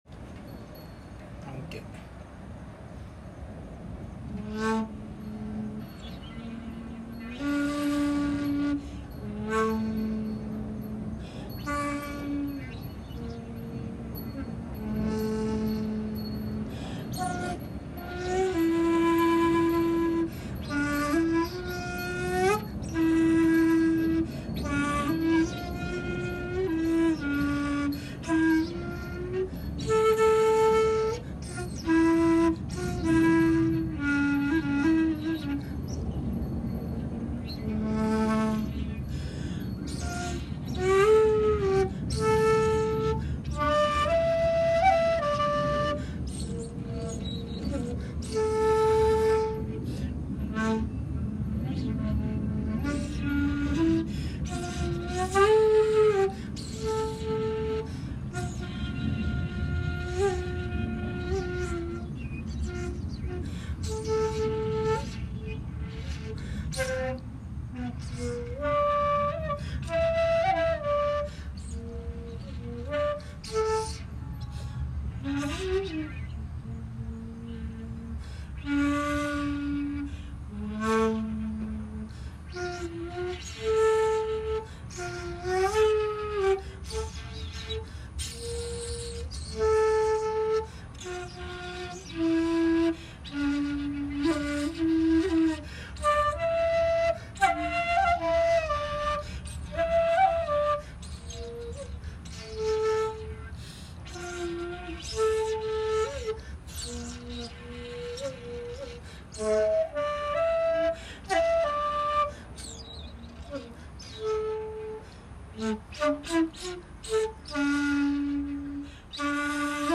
そして尺八を吹奏しました。
ちょうど同じように休憩していたお遍路さんが聞いてくださいました。
◆◆ （尺八音源：龍光院にて「手向」）